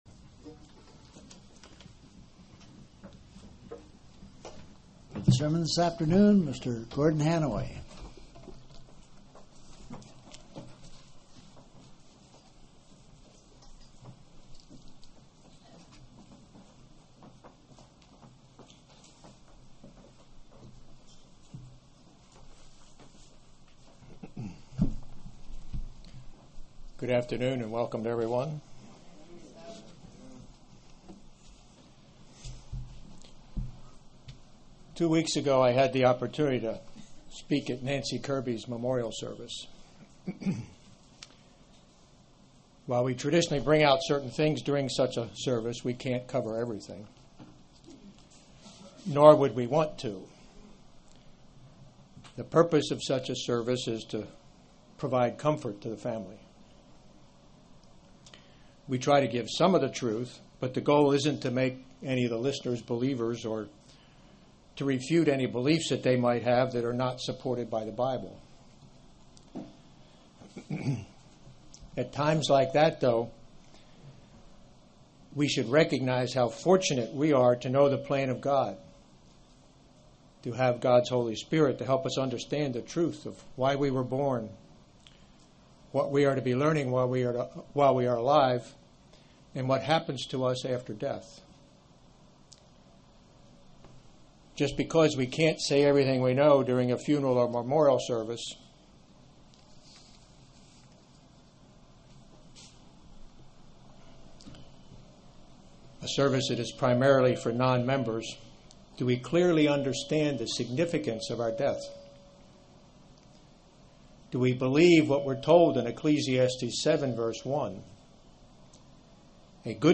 Given in Tampa, FL